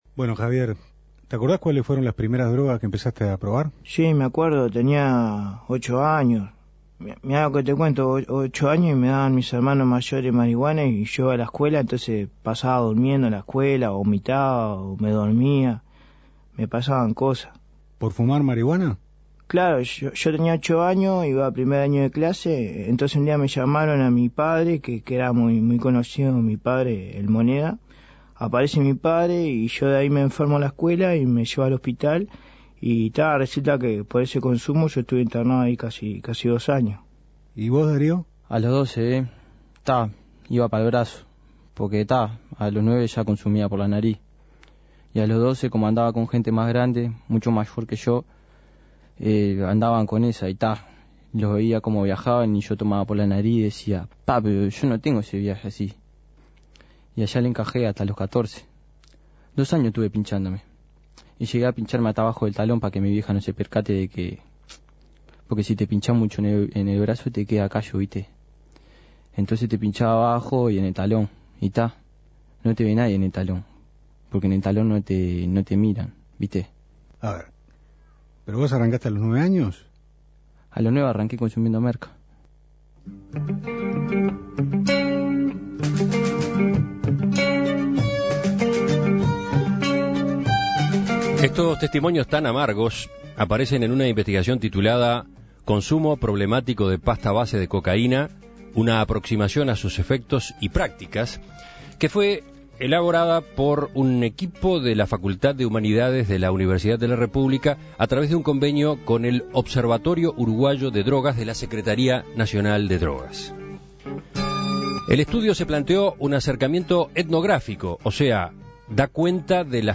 Testimonios